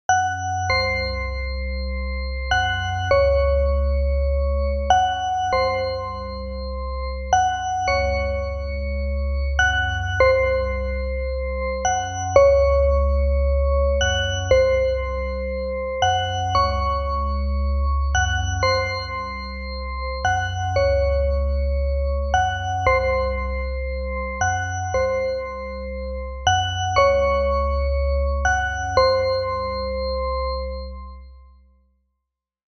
Download Suspense Horror sound effect for free.
Suspense Horror